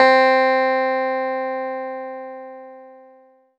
CLAVI1.06.wav